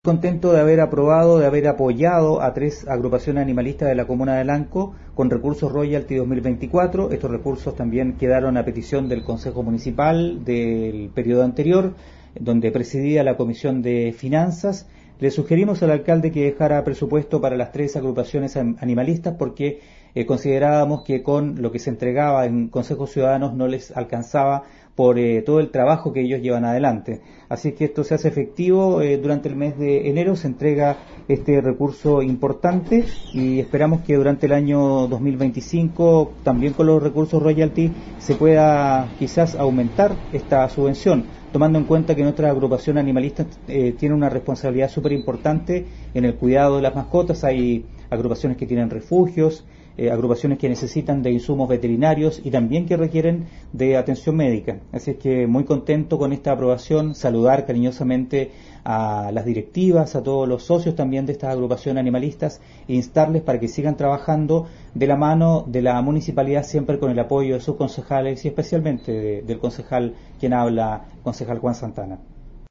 Conversamos con el concejal Juan Santana Paredes, quien señala que el año pasado como presidente de la comisión de Finanzas del Concejo Municipal, solicitó directamente a la administración municipal en reuniones de concejo, aumentar los apoyos económicos hacia estas organizaciones, y que se reserve la cifra de un millos y medio de pesos de los recursos Royalty para este ítem, cuestión que fue ratificada en pleno en esta última reunión.